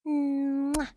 亲吻.wav
亲吻.wav 0:00.00 0:00.98 亲吻.wav WAV · 84 KB · 單聲道 (1ch) 下载文件 本站所有音效均采用 CC0 授权 ，可免费用于商业与个人项目，无需署名。
人声采集素材/人物休闲/亲吻.wav